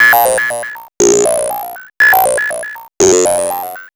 MOOGHAT15.wav